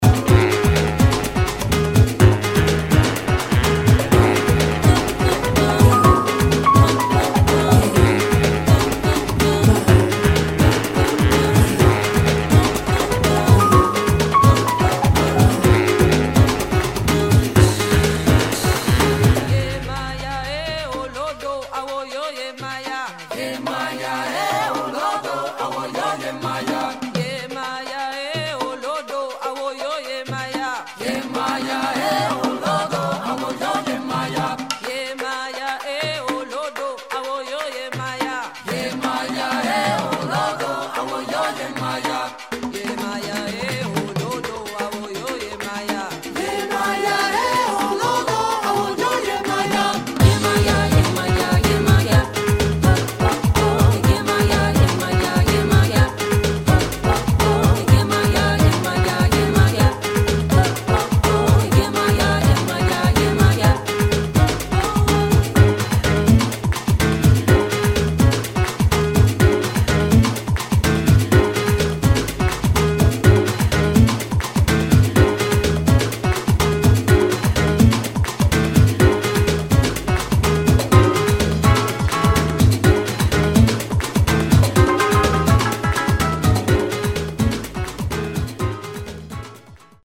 [ JAZZ / AFRO / CUBAN ]